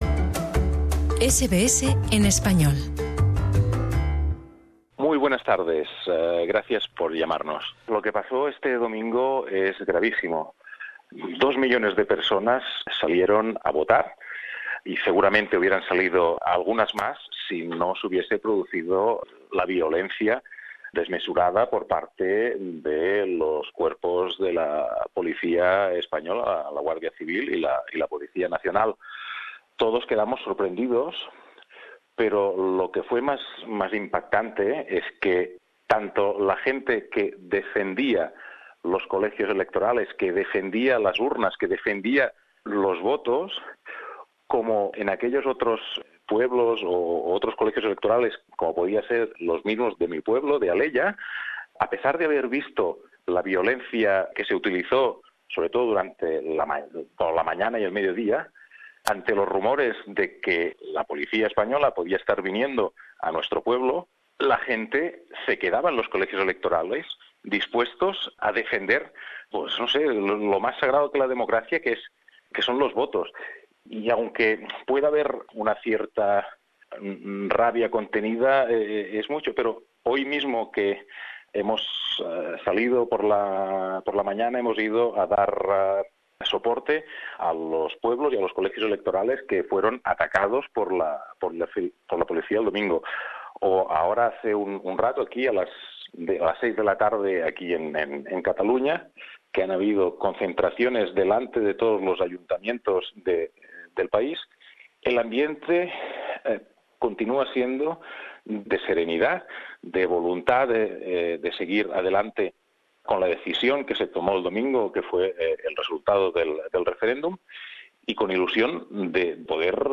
Entrevista con el alcalde del pueblo catalán de Alella, Andreu Francisco.